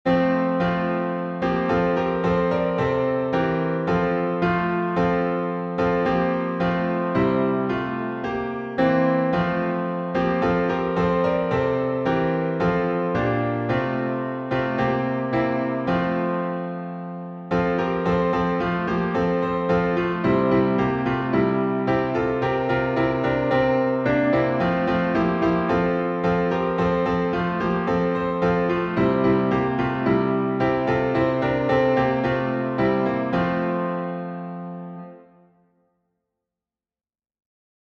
Since I Have Been Redeemed — in F major.